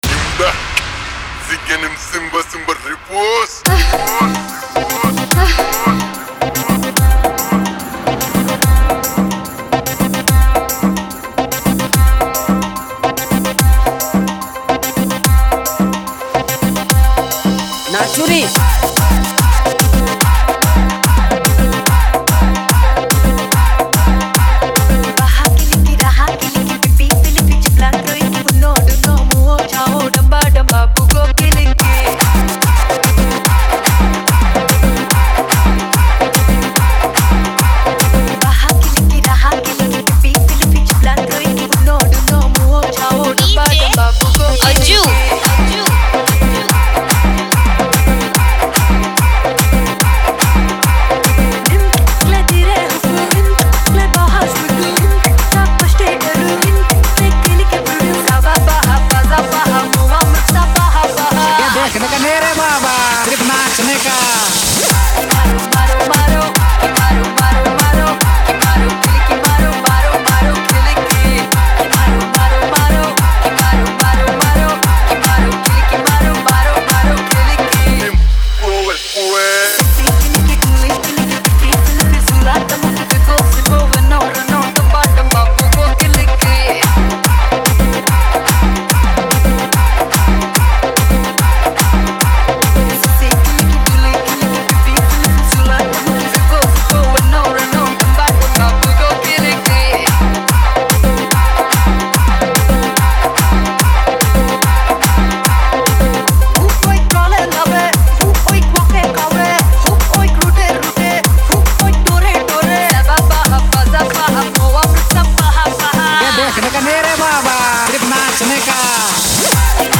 Tapori Dance Mix